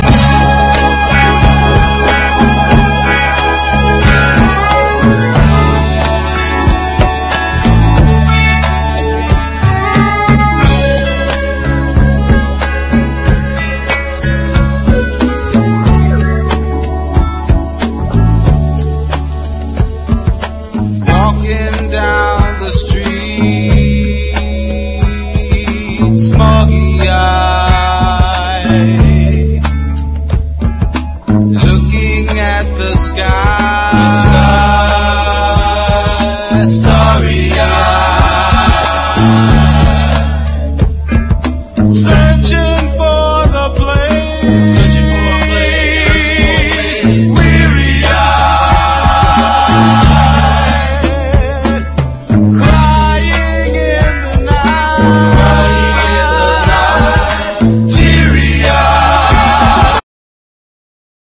SOUL / SOUL / 70'S～ / FREE SOUL / DANCE CLASSIC / DISCO